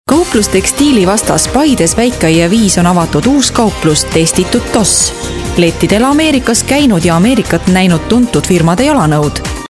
Sprecherin estnisch (Muttersprache) für Werbung, Voice over, Imagefilm, Industriefilm etc.
Sprechprobe: Werbung (Muttersprache):
Professionell female estonian voice over artist